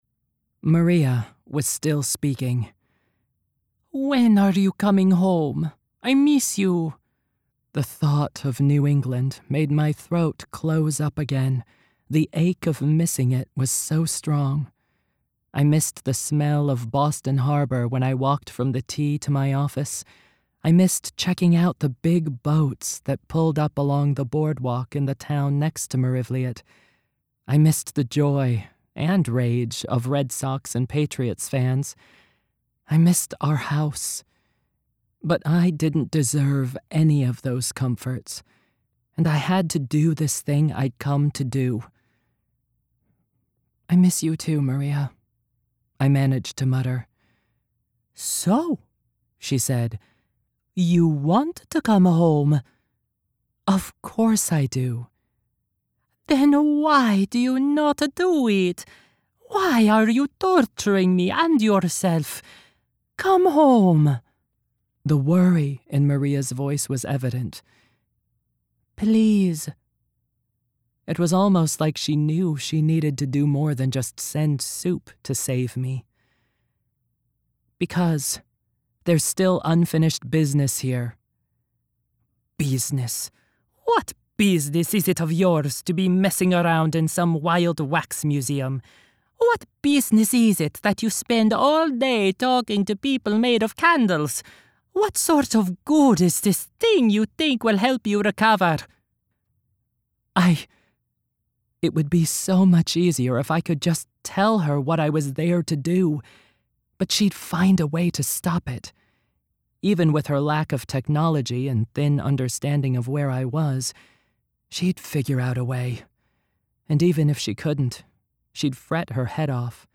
Narration Samples
1st Person
Two women + Italian dialect